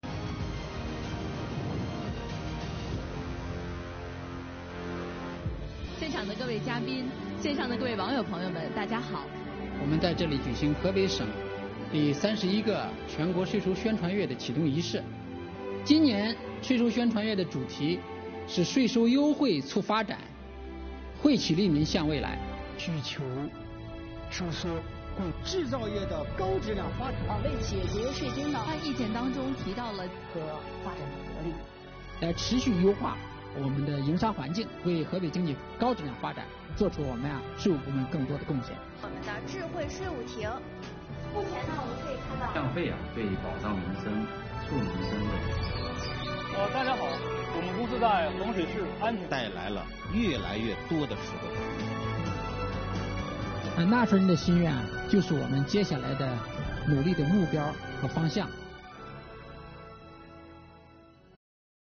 现场访谈，视频连线，全面启动税收宣传月